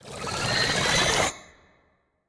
Sfx_creature_brinewing_suckup_01.ogg